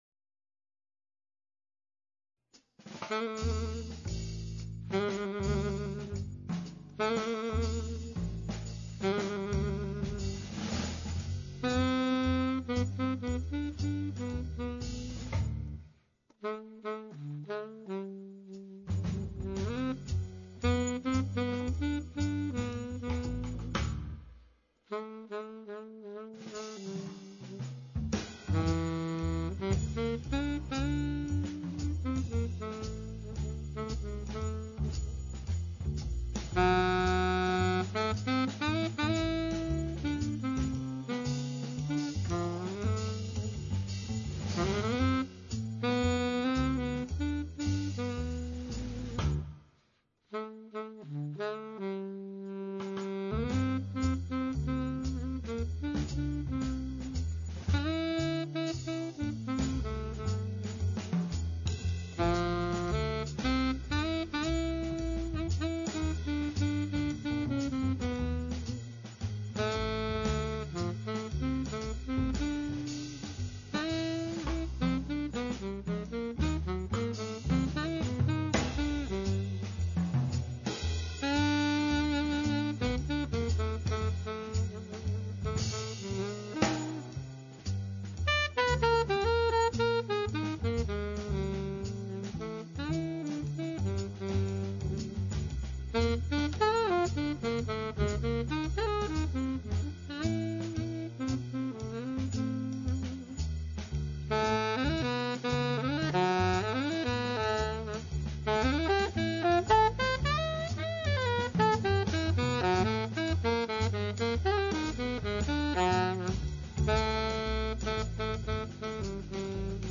alto and sopranino saxophones
piano
bass
drums